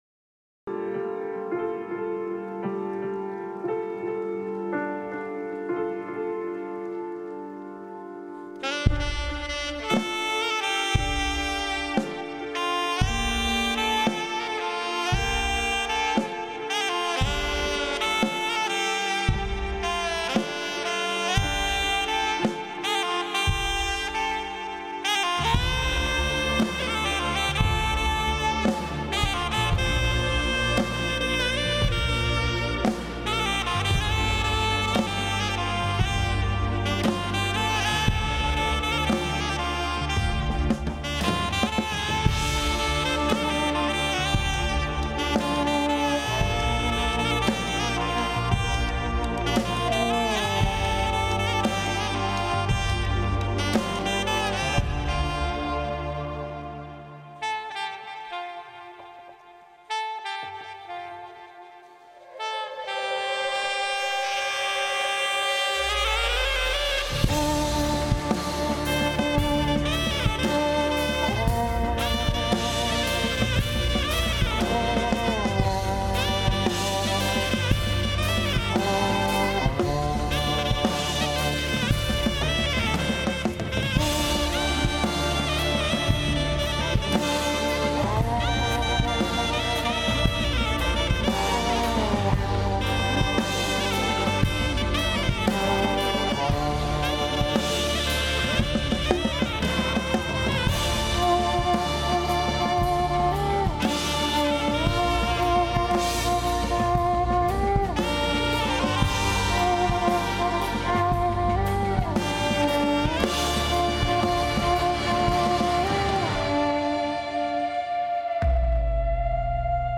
classical finesse with electronic dance floor fillers.